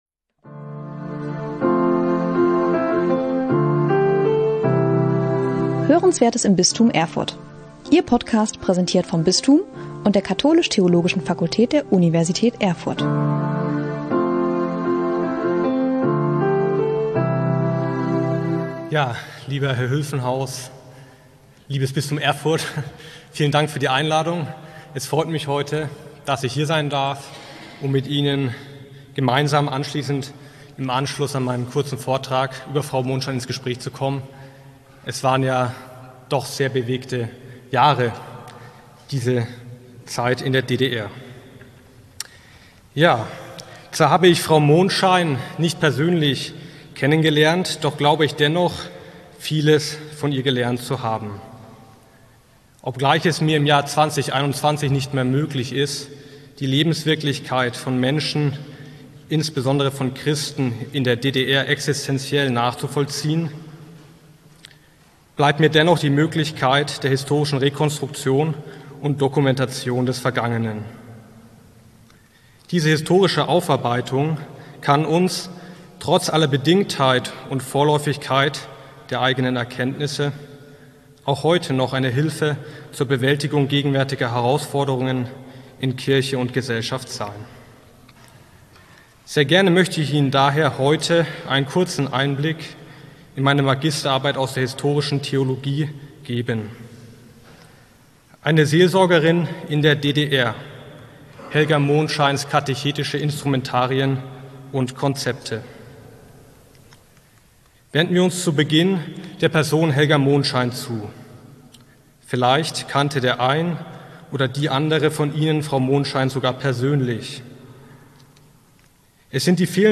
Spannende Vorträge und Interviews aus dem Bistum Erfurt, aufgenommen bei Veranstaltungen des Katholischen Forums, der katholisch-theologischen Fakultät Erfurt sowie Fortbildungen im Bistum Erfurt.